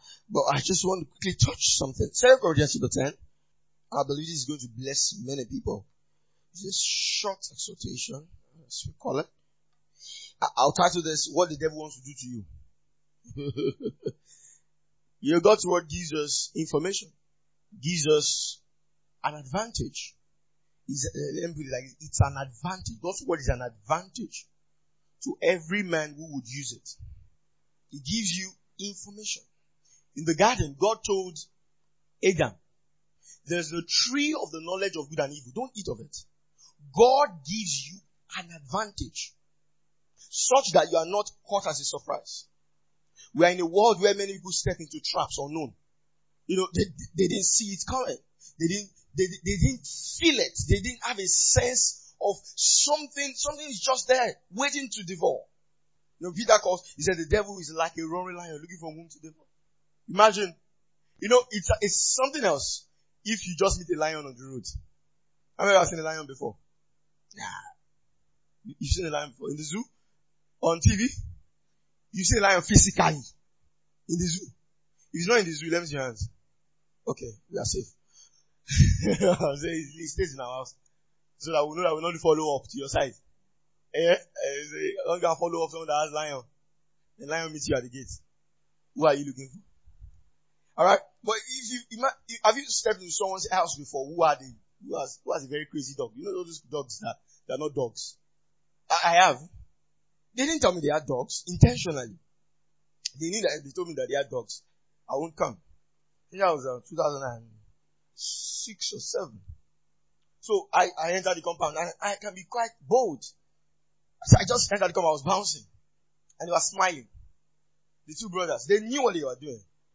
2024 Glorious House Church Teachings.